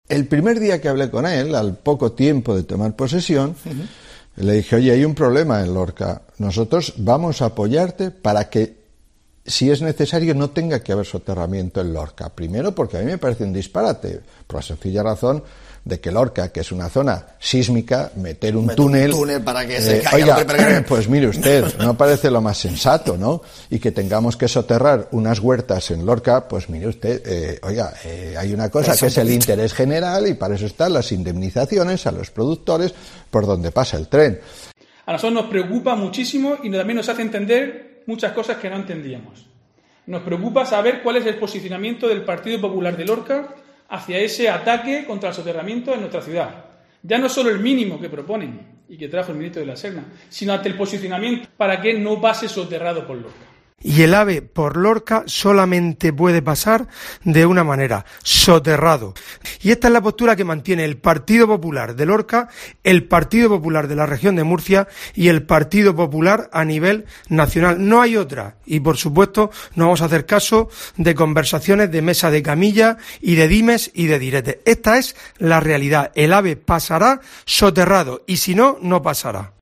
Rafael Hernando senador del PP, Diego José Mateos alcalde de Lorca y Fulgencio Gil, portavoz del PP en Lorca